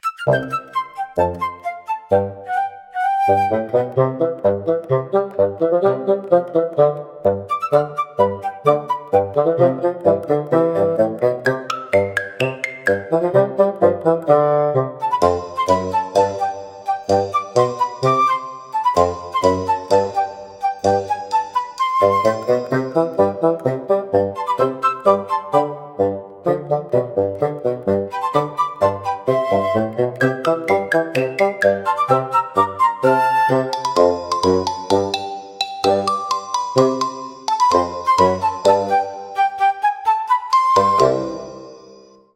視聴者の笑いを誘い、軽快で親しみやすいムードを演出しながら、退屈を吹き飛ばします。